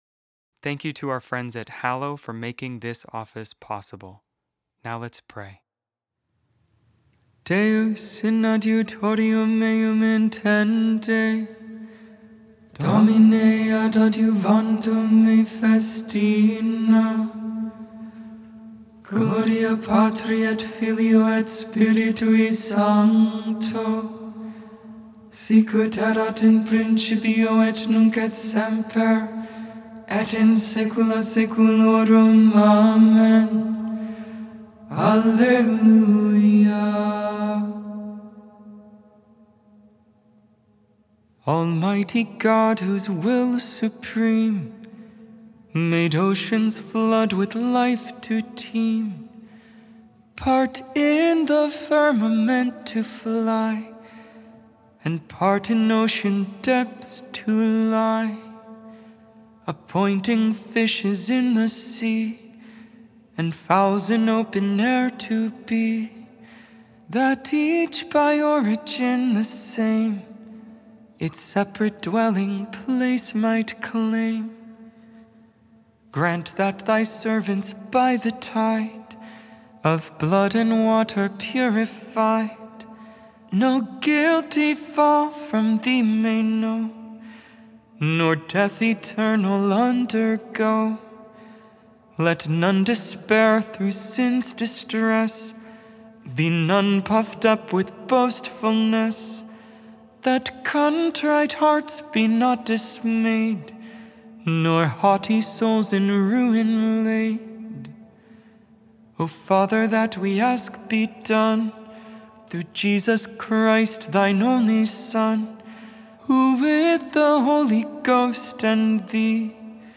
Vespers, Evening Prayer on the 31st Thursday in Ordinary Time, November 6, 2025.Made without AI. 100% human vocals, 100% real prayer.